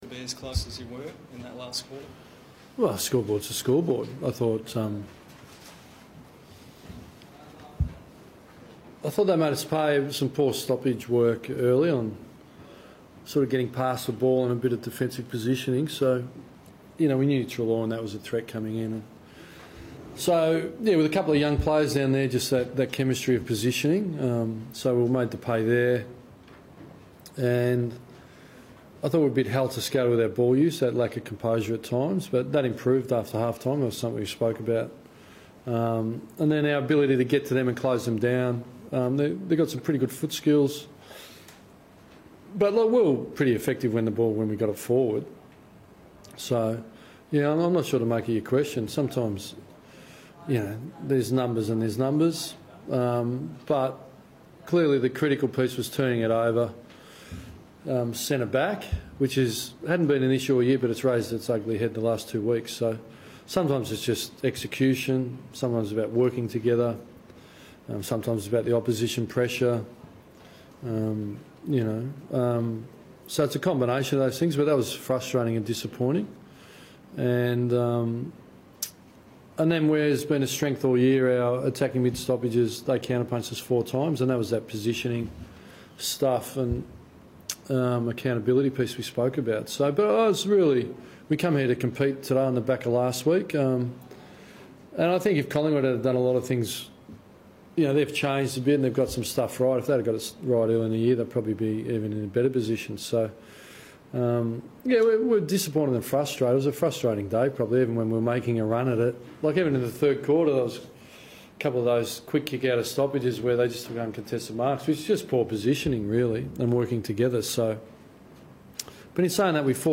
Ross Lyon chats to the media after Freo's clash against the Pies.